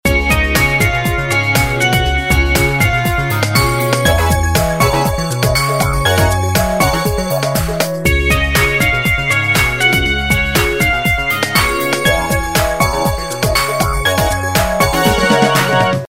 جلوه های صوتی
رینگتون های اورجینال گوشی